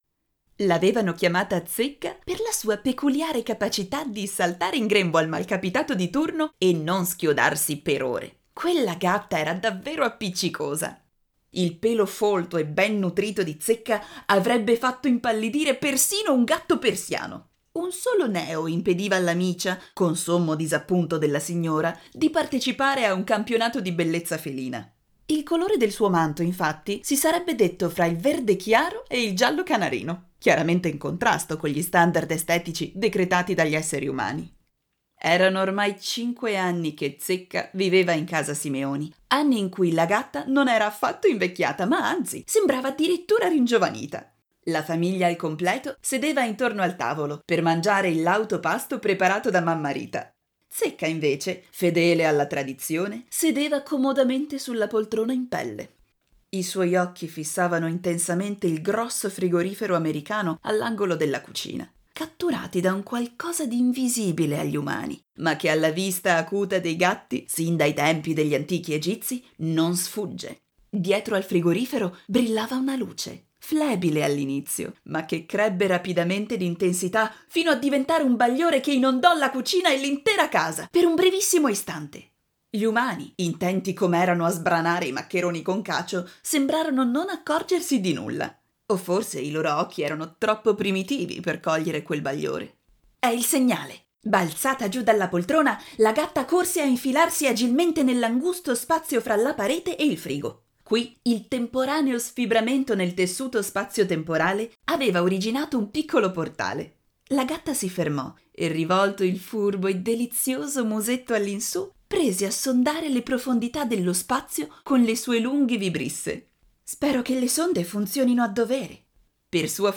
Ascolta l’anteprima (audiolettrice: